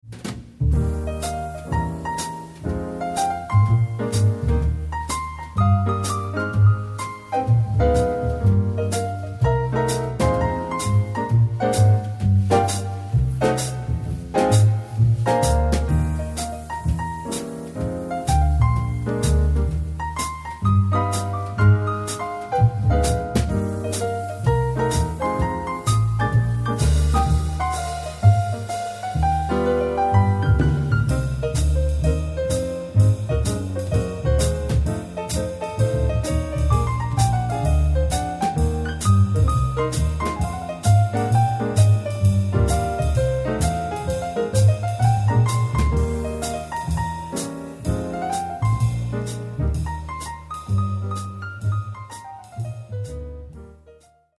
3-piece Jazz Band for Hire
Piano, Double Bass, Drums